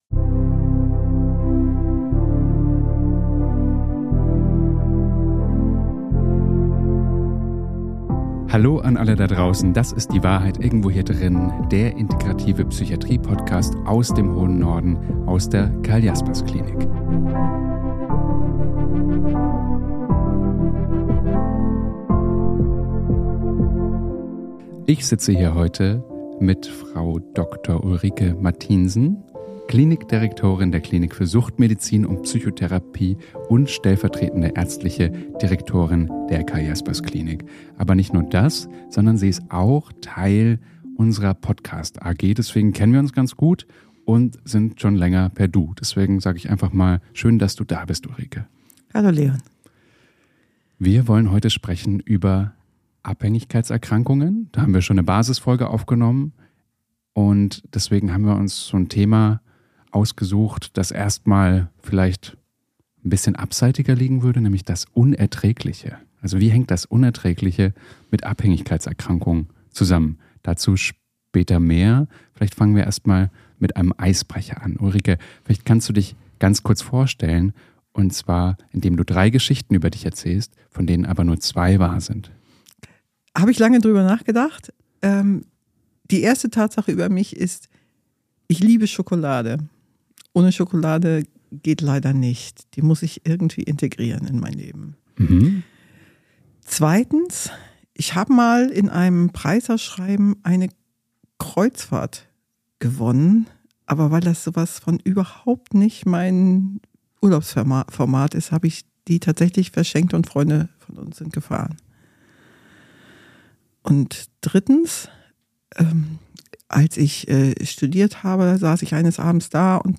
#15 ABHÄNGIGKEITSERKRANKUNGEN Experten-Talk ~ Die Wahrheit Irgendwo Hier Drinnen Podcast